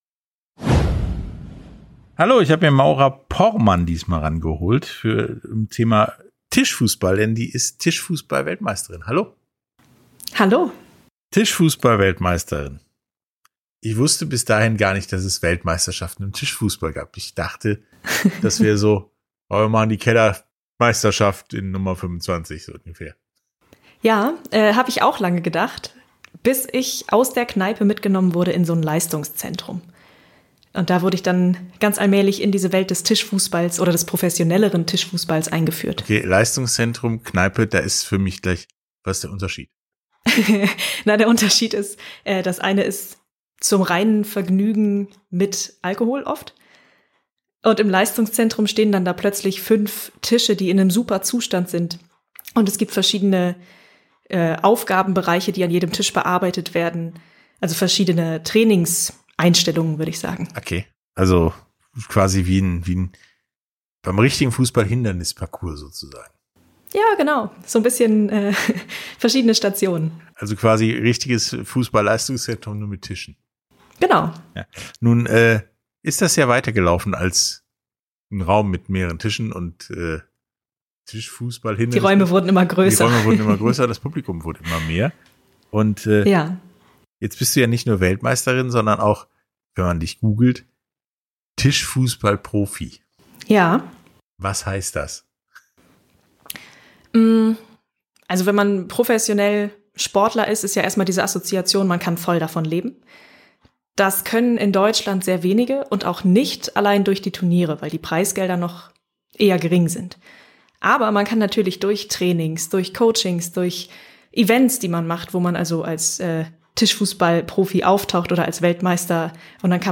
Sportstunde - Interviews in voller Länge